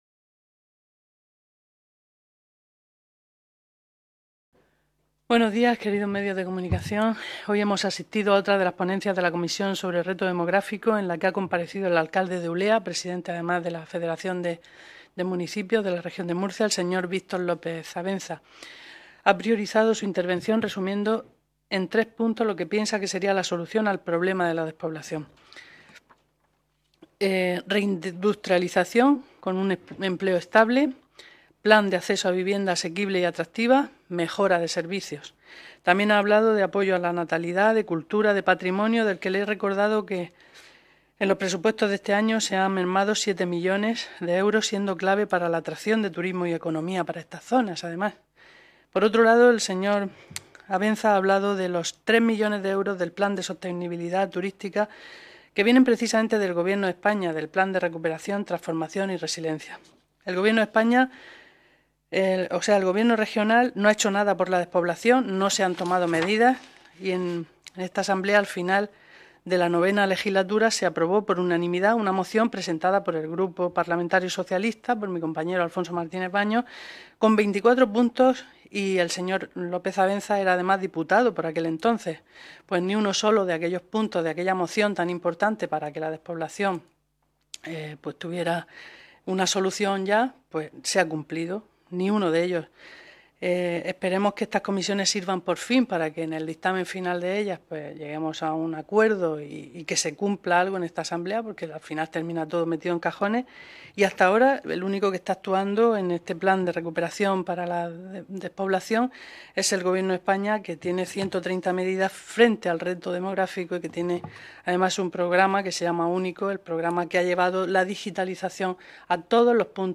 Ruedas de prensa tras la Comisión Especial de Estudio para abordar el Reto Demográfico y la Despoblación en la Región de Murcia